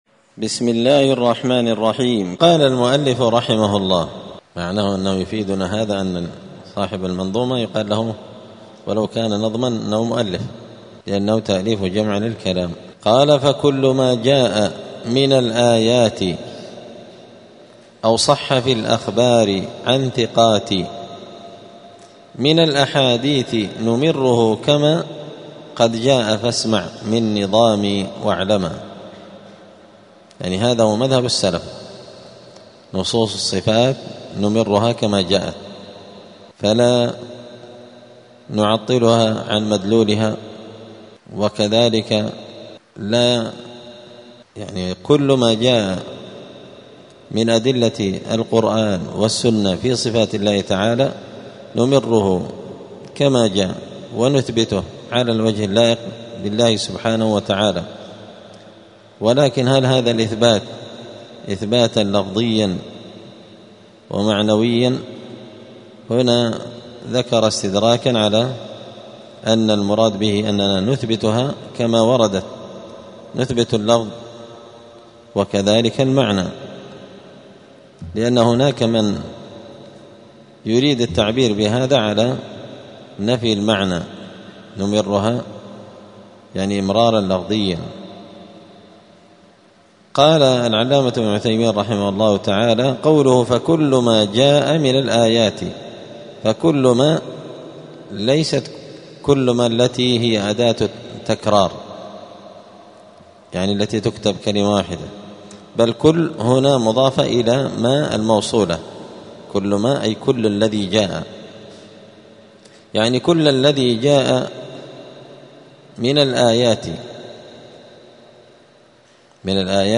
دار الحديث السلفية بمسجد الفرقان قشن المهرة اليمن
22الدرس-الثاني-والعشرون-من-شرح-العقيدة-السفارينية.mp3